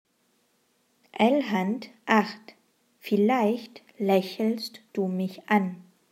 Übungsätze
Satz 1 Langsam